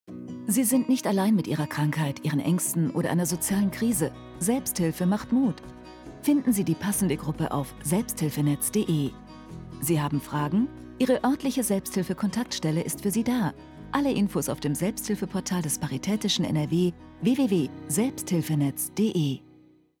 Radiospot Paritätischer Wohlfahrtsverband NRW - Rheinklang Media